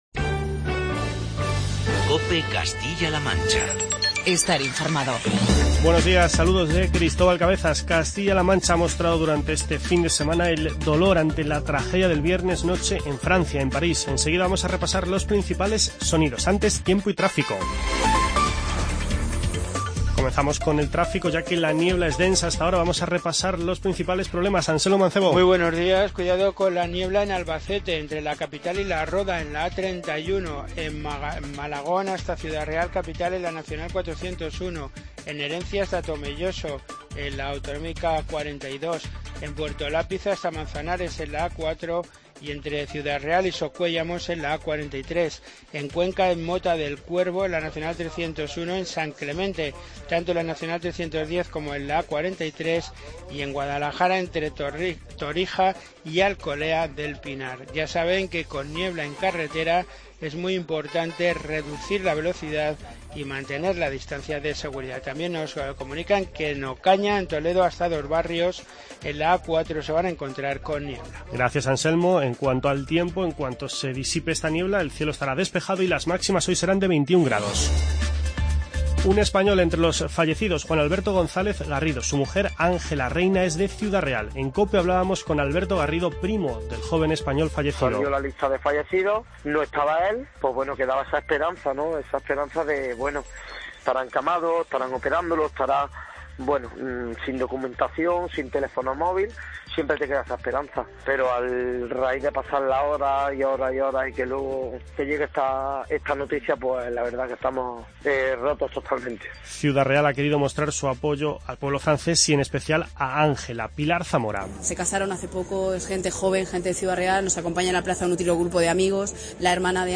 Informativo regional y provincial
Castilla-La Mancha ha mostrado durante este fin de semana el dolor ante la tragedia de París. Repasamos los sonidos más destacados de las últimas horas